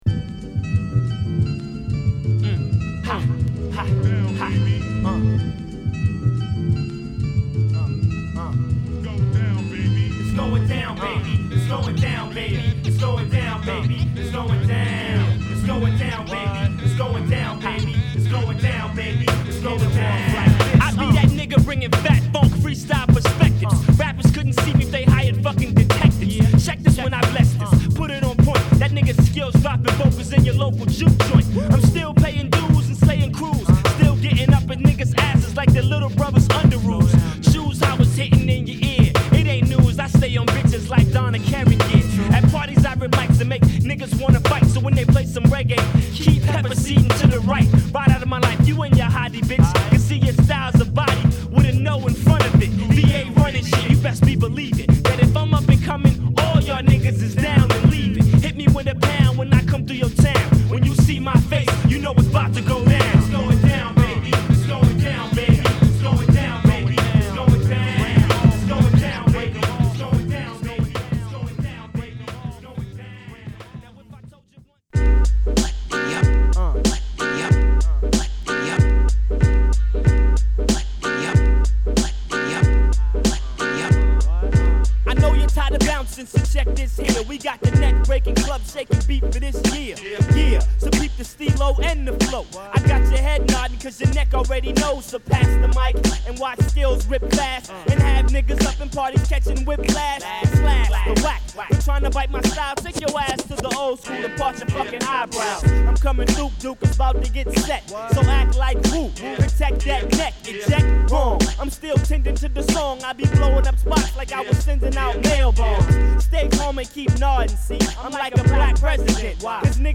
＊試聴はA2→A3→B4(別コピーからの参考音源)です。